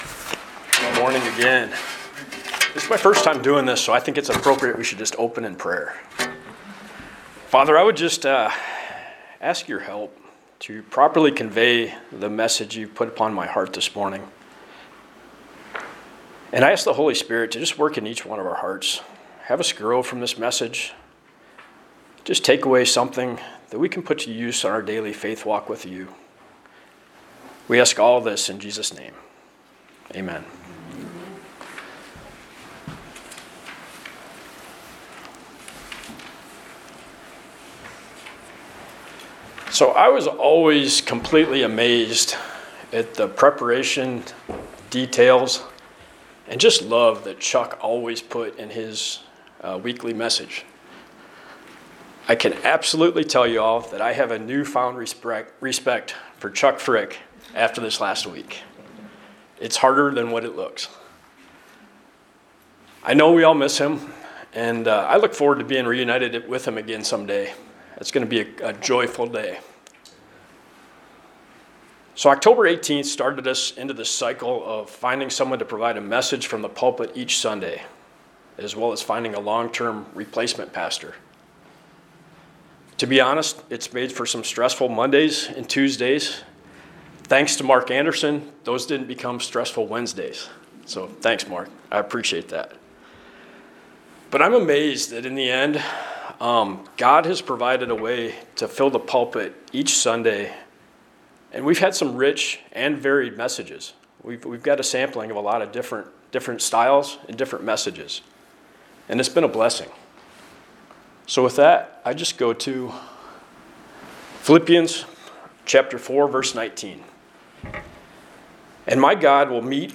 Passage: 2 Corinthians 12:9 Service Type: Sunday Morning Worship